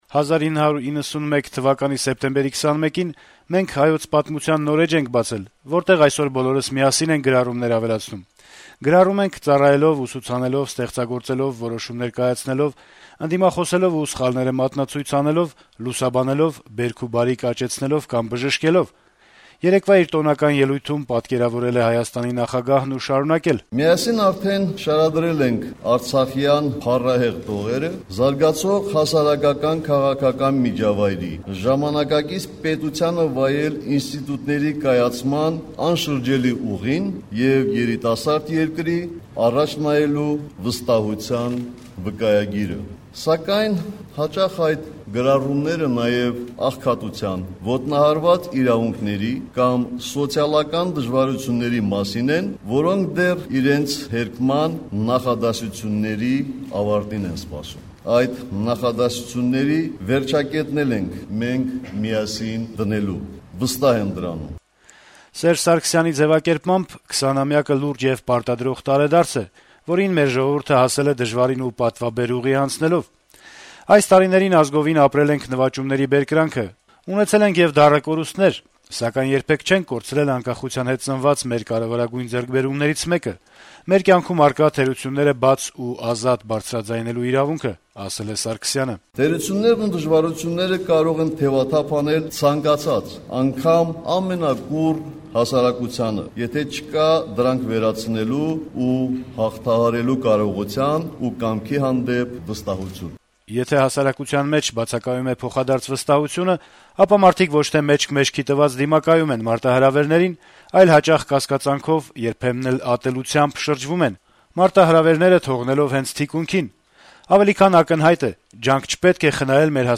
Հայաստանի նախագահ Սերժ Սարգսյանը ՀՀ անկախության 20-րդ տարեդարձի առթիվ երեքշաբթի երեկոյան տոնական ընդունելություն էր հրավիրել: